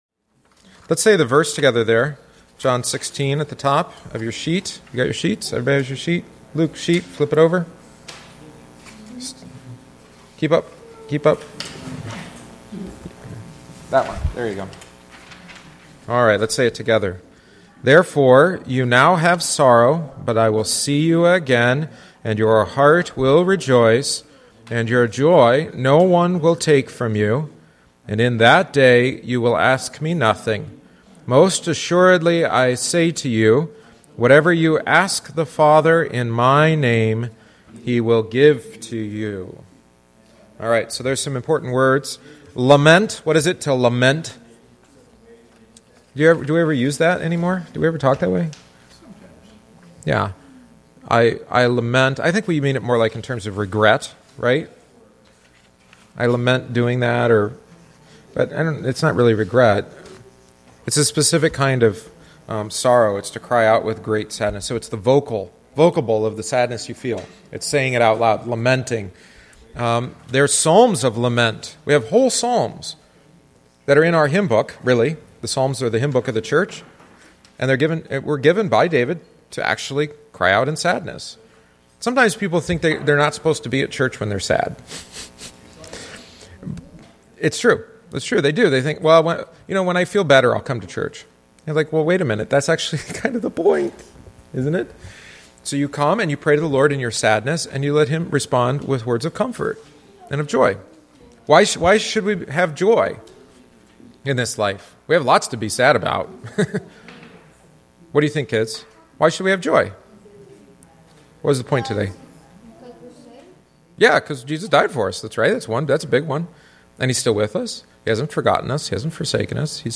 “Sorrow turned to Joy” Bible Study on John 16:16-22